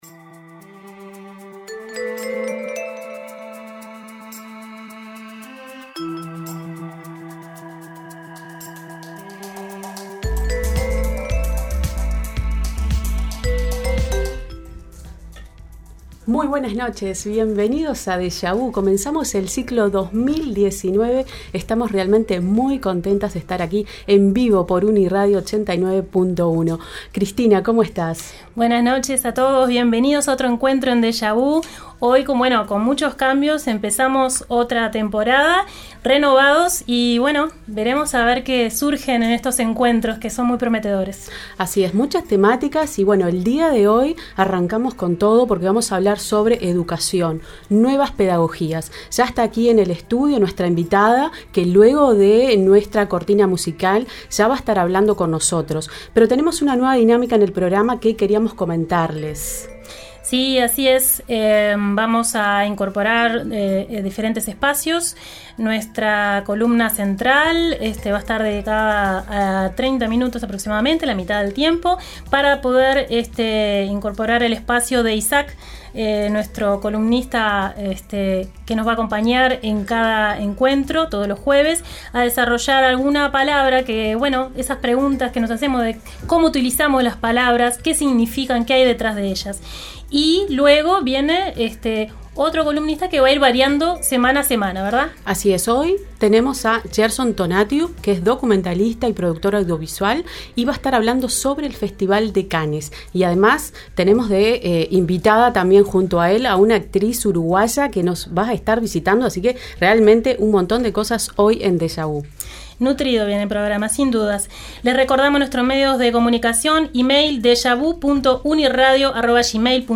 En la entrevista central